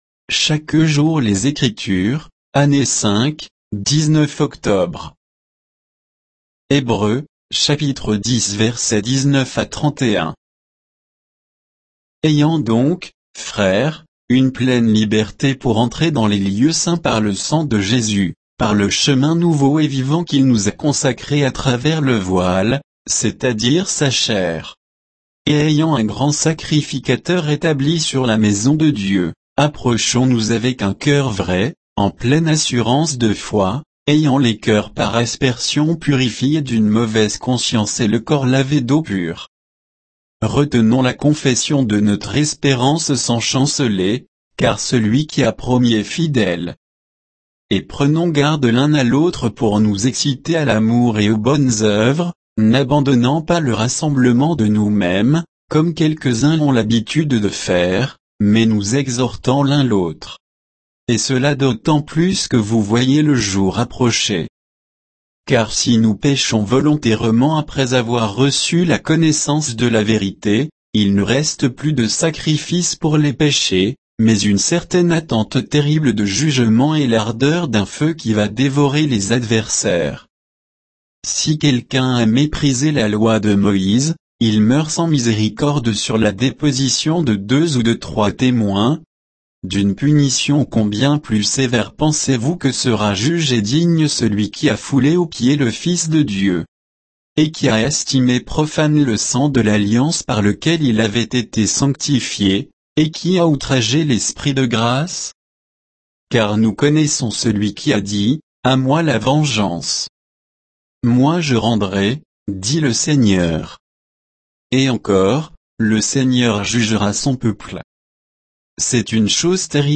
Méditation quoditienne de Chaque jour les Écritures sur Hébreux 10, 19 à 31